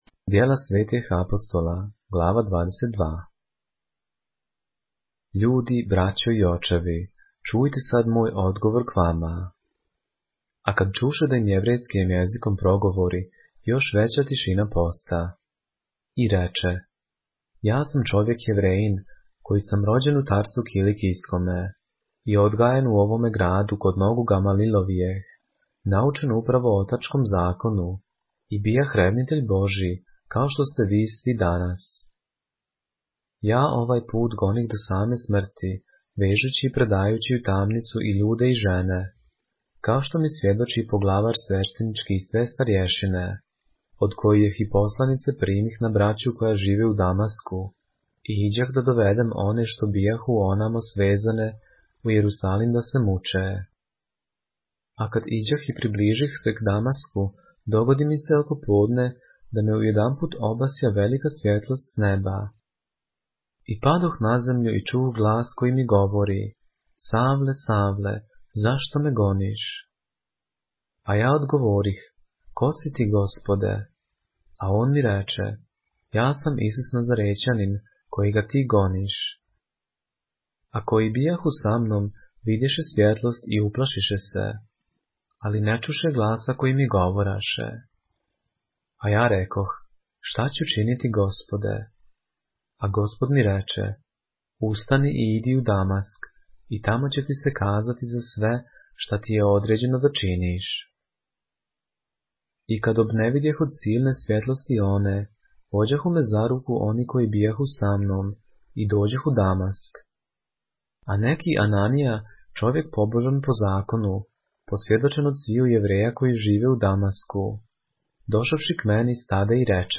поглавље српске Библије - са аудио нарације - Acts, chapter 22 of the Holy Bible in the Serbian language